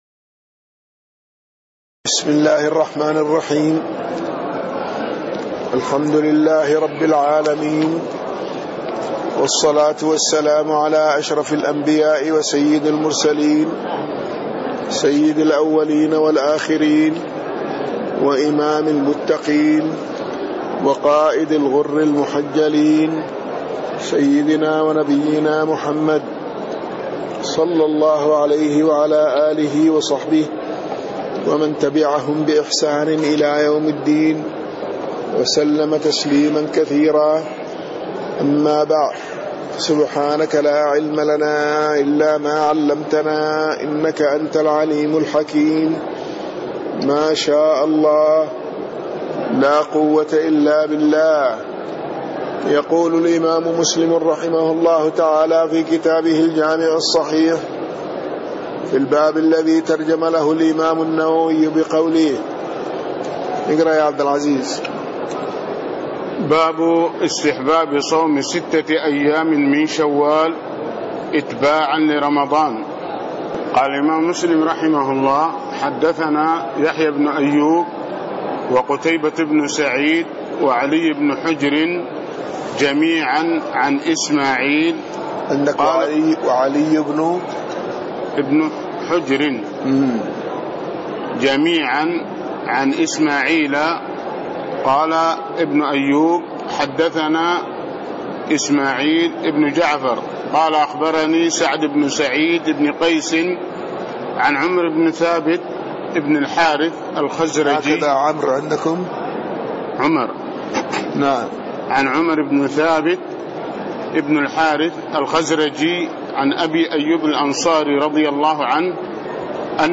تاريخ النشر ٢١ رمضان ١٤٣٣ هـ المكان: المسجد النبوي الشيخ